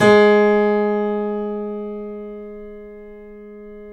Index of /90_sSampleCDs/Roland L-CD701/KEY_Steinway ff/KEY_Steinway M